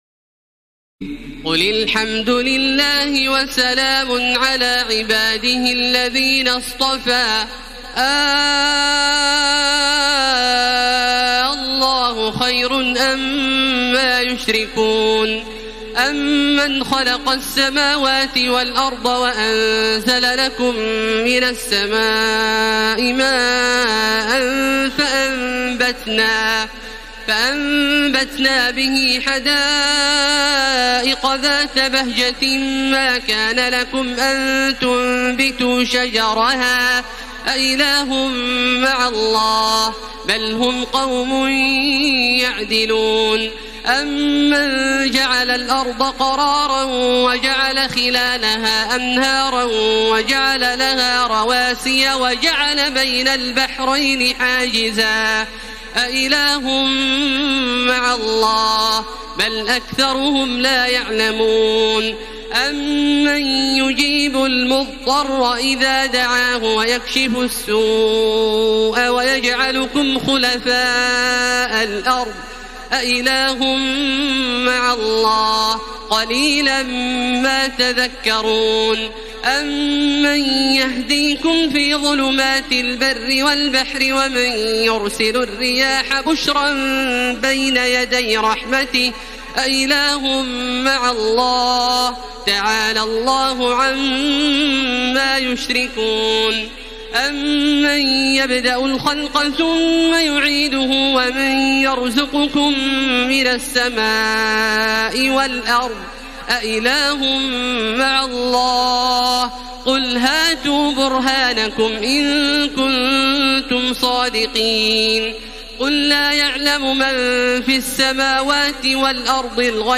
تراويح الليلة التاسعة عشر رمضان 1433هـ من سورتي النمل(59-93) و القصص(1-50) Taraweeh 19 st night Ramadan 1433H from Surah An-Naml and Al-Qasas > تراويح الحرم المكي عام 1433 🕋 > التراويح - تلاوات الحرمين